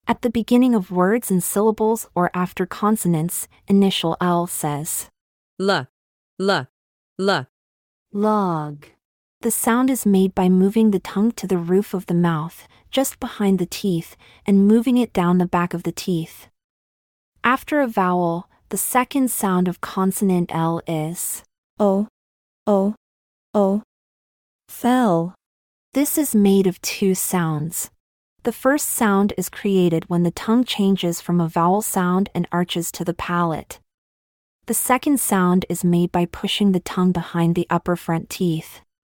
At the beginning of words and syllables or after consonants, initial L says: /L/, /L/, /L/ log. The sound is made by moving the tongue to the roof of the mouth, just behind the teeth, and moving it down the back of the teeth. After a vowel, the second sound of consonant L is: /əL/, /əL/, /əL/, fell.